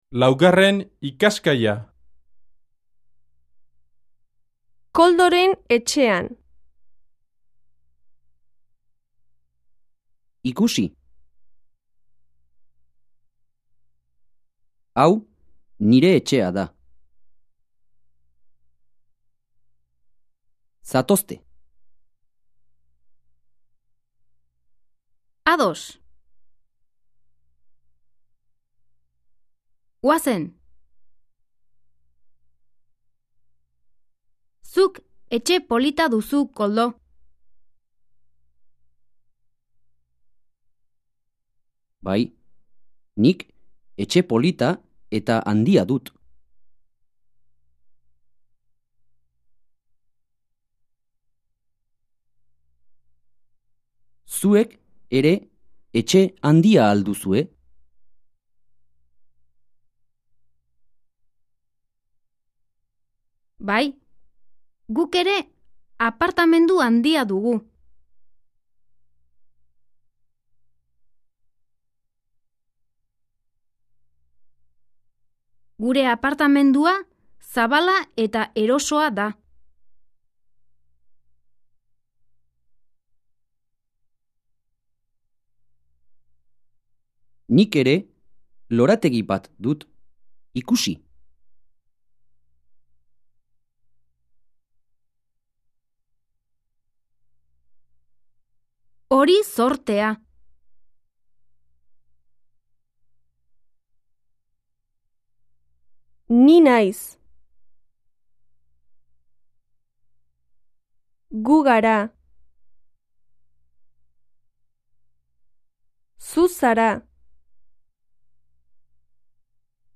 Диалог